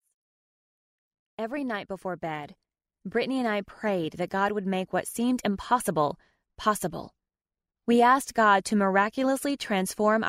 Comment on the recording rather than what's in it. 6.37 Hrs. – Unabridged